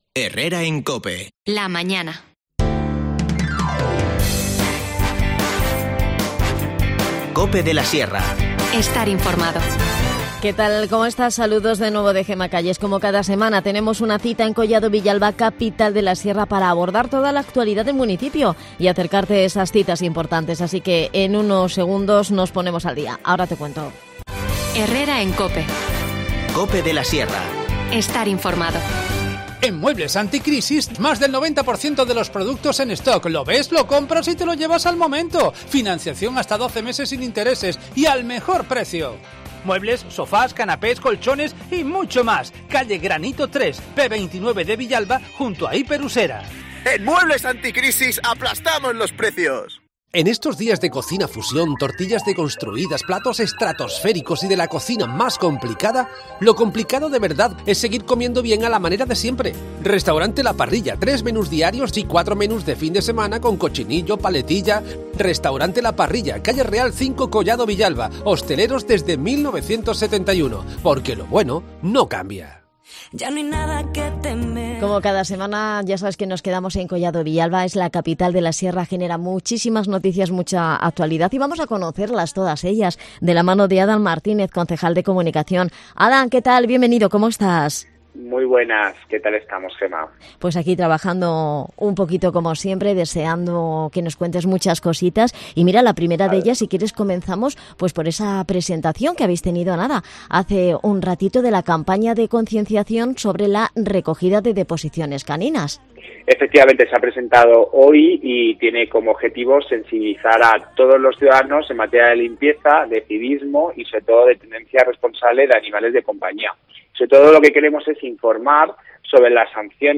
AUDIO: Adan Martínez, concejal de Comunicación en Collado Villalba, Capital de la Sierra, nos habla en el programa de la Campaña de...